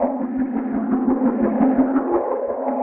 Index of /musicradar/rhythmic-inspiration-samples/85bpm